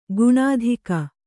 ♪ guṇādhika